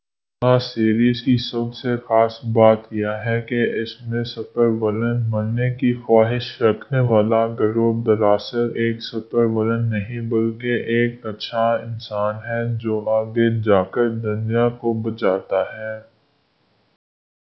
deepfake_detection_dataset_urdu / Spoofed_TTS /Speaker_17 /103.wav